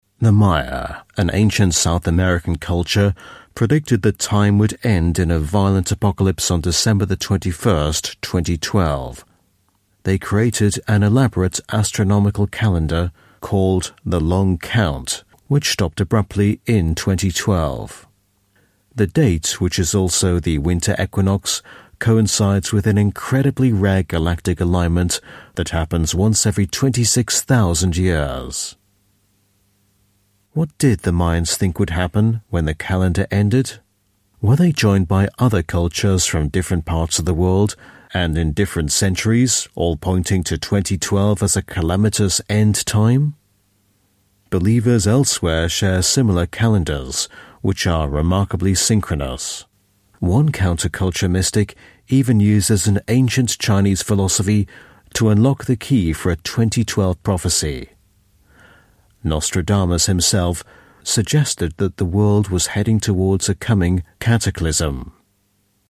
Dokus
Native Speaker